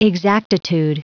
Prononciation du mot exactitude en anglais (fichier audio)
Prononciation du mot : exactitude